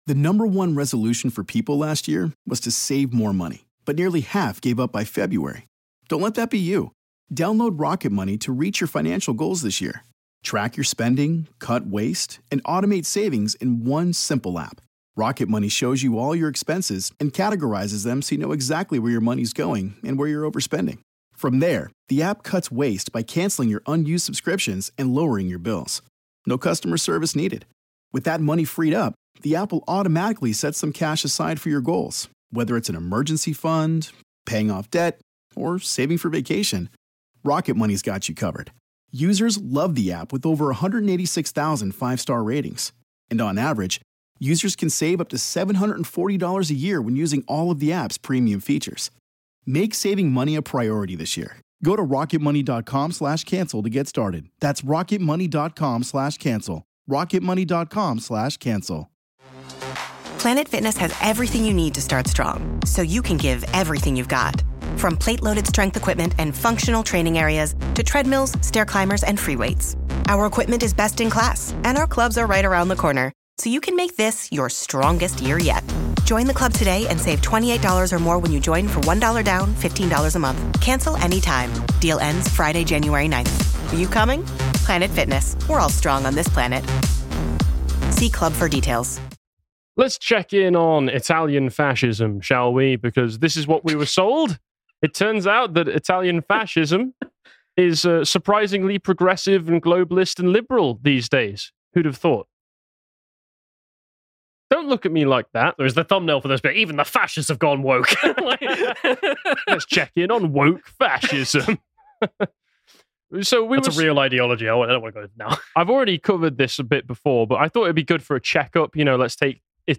Pre-recorded Thursday 31/09/23.